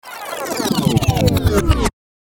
Download Vinyl sound effect for free.
Vinyl